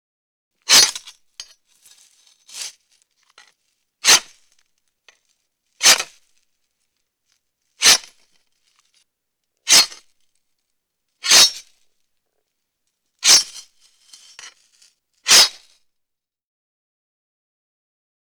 Bayonet Into Flesh Sound
horror